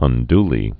(ŭn-dlē, -dy-)